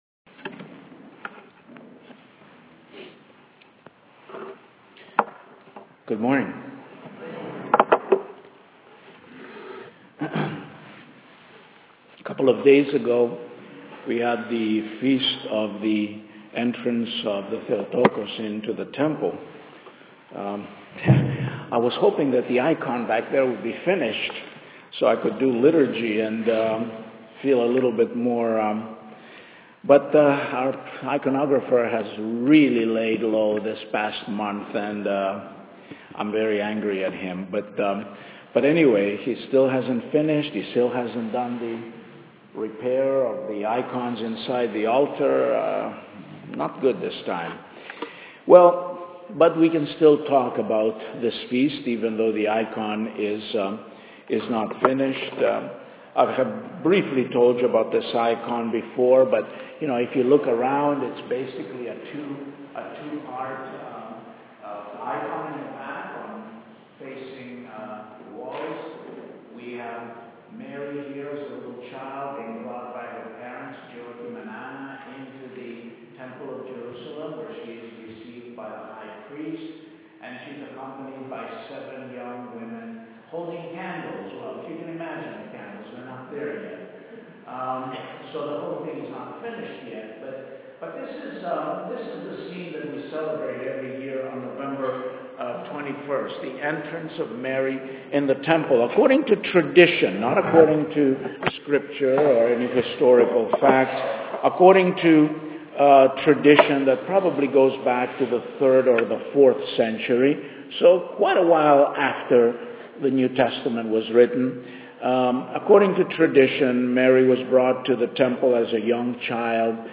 Mary is a safeguard against the excessive intellectualization of the Christian faith…. The audio file of my sermon on the theme of why we need Mary is here: